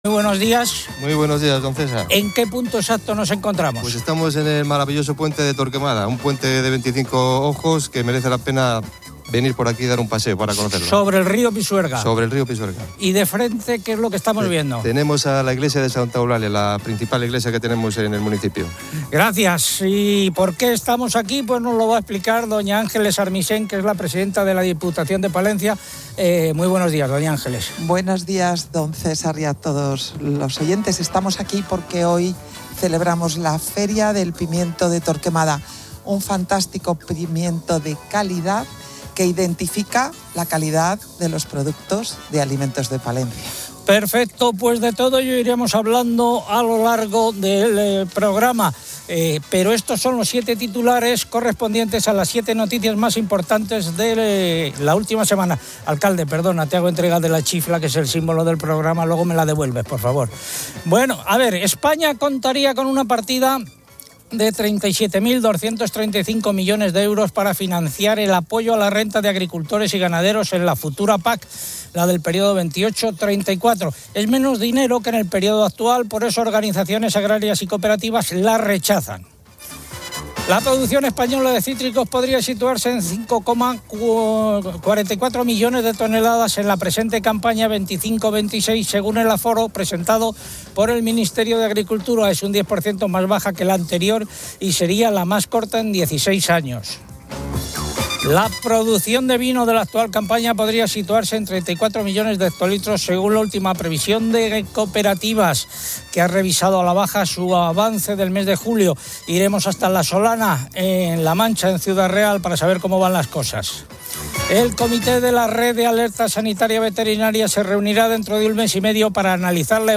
Agropopular 08:30H | 20 SEP 2025 | Agropopular COPE AgrpopulAr se emite desde Torquemada por la Feria del Pimiento.